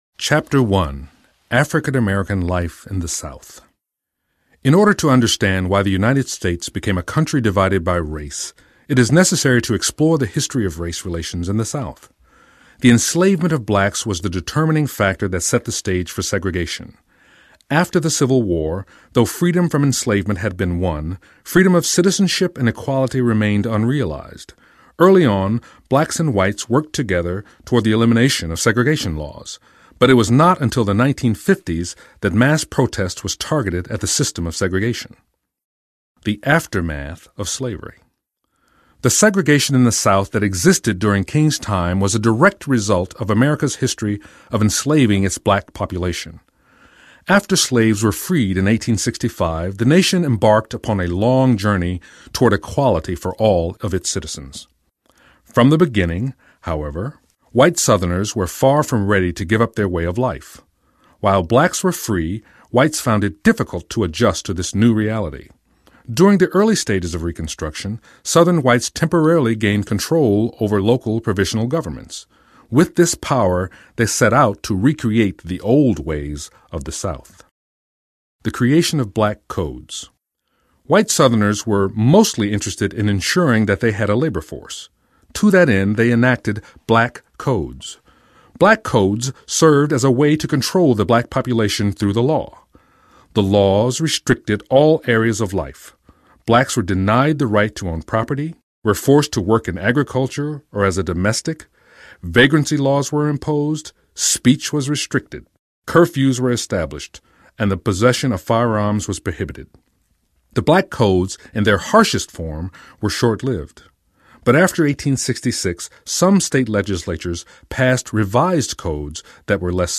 The Everything Martin Luther King Jr. Book (Everything Books) Audiobook
Narrator